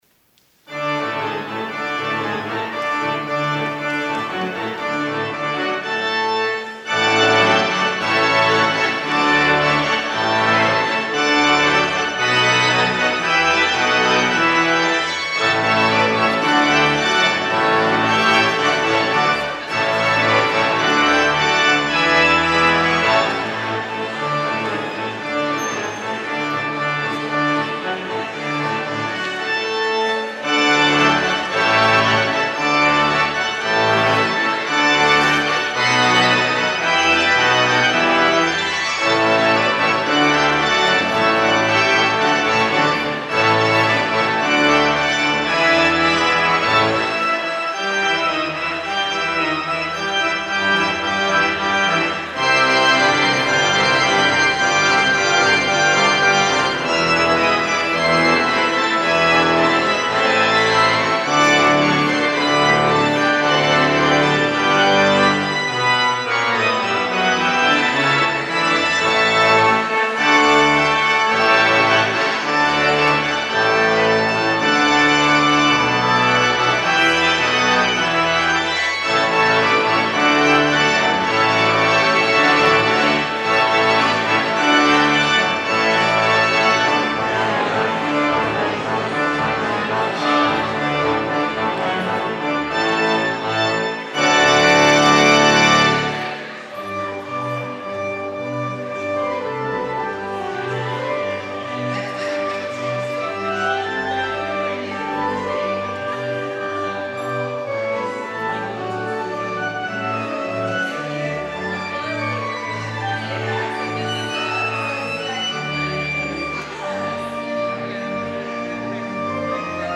clarinet
organ
VOLUNTARY